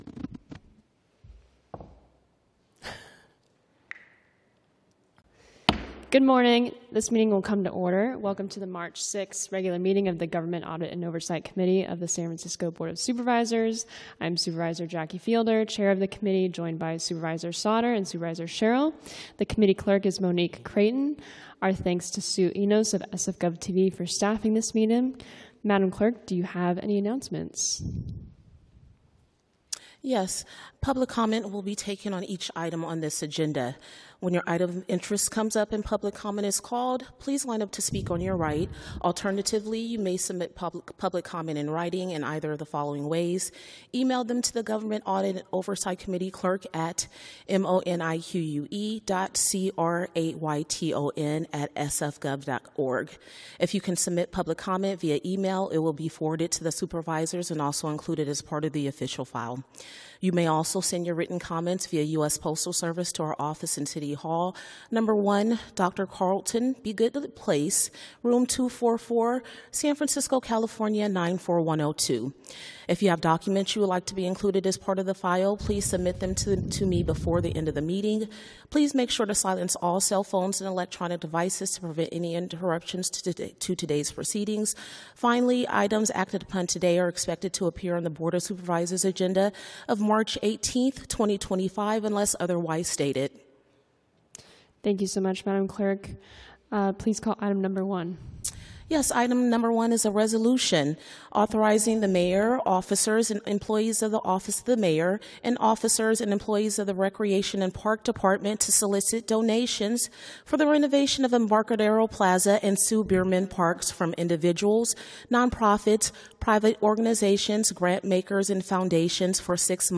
BOS- Government Audit and Oversight Committee - Regular Meeting - Mar 06, 2025